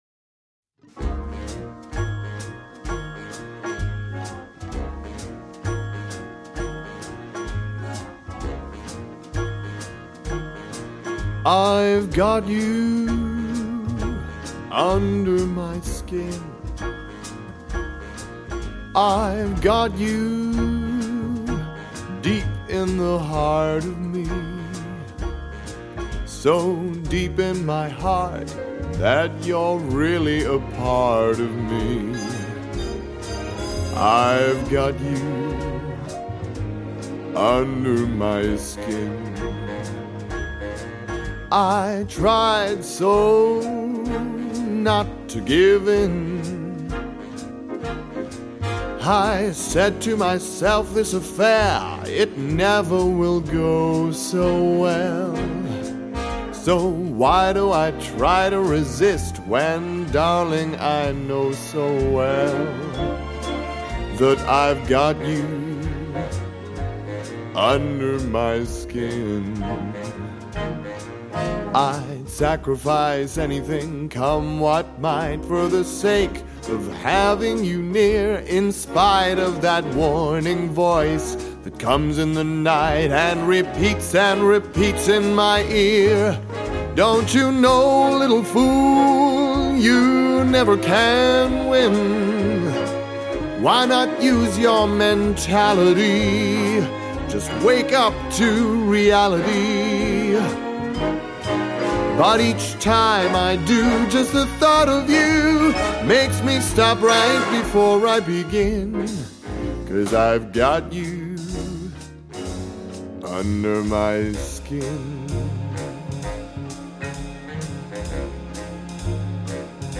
• Features a full-swing orchestra with lush strings